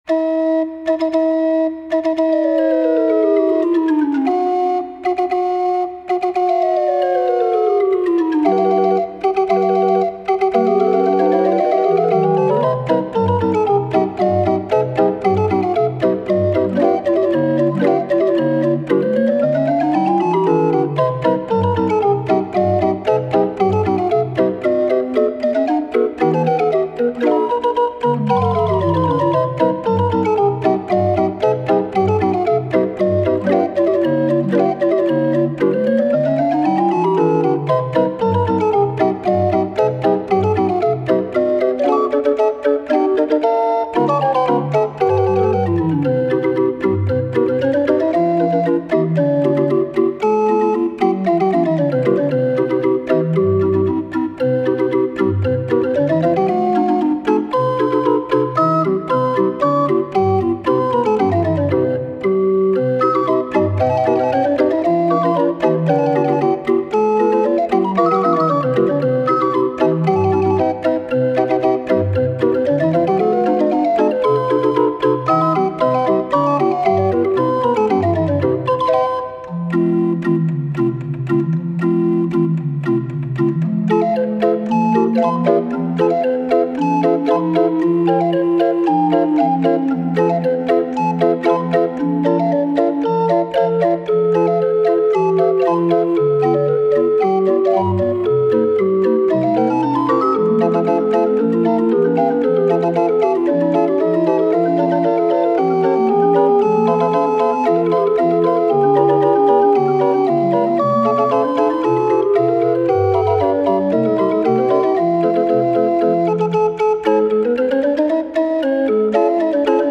36 street organ